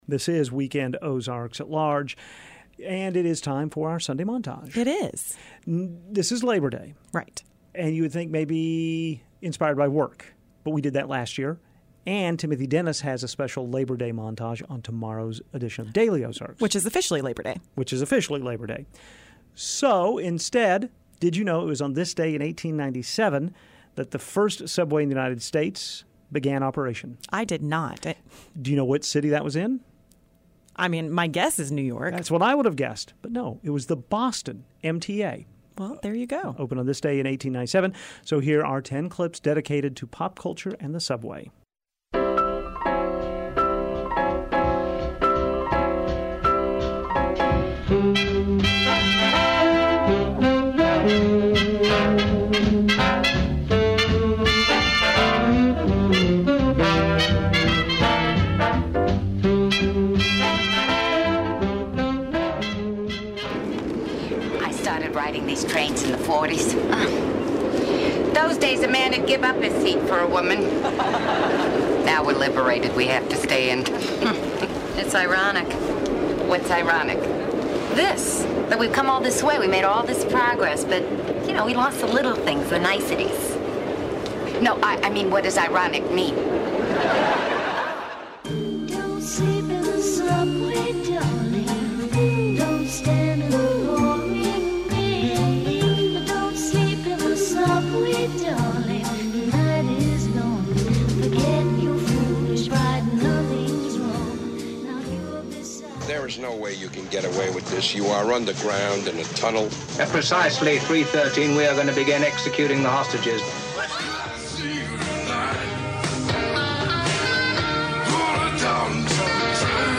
Here are the clips for today's montage dedicated to the subway: 1. Duke Ellington performs one of America's best compostions, Take the A Train. 2.
Tom Waits' Downtown Train. 6.The most famous clip from the drive-in classic Warriors. 7. The Kingston Trio sings a song about the Boston subway. 8.